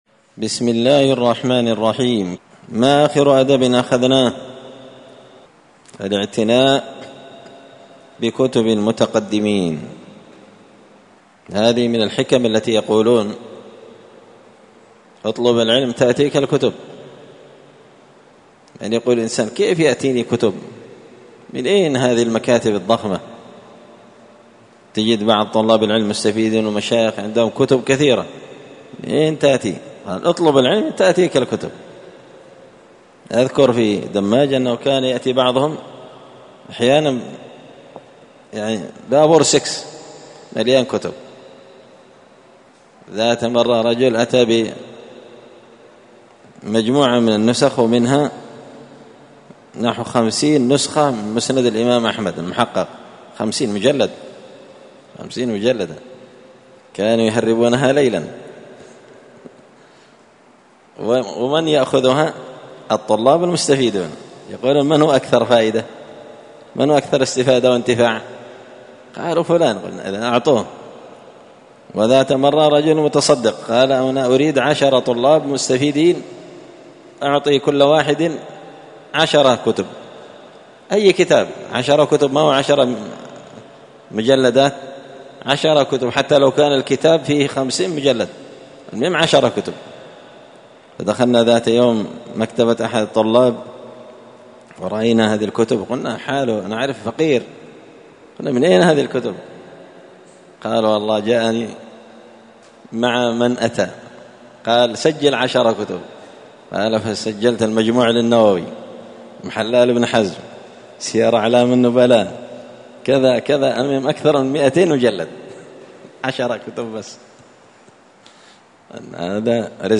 تعليق وتدريس الشيخ الفاضل: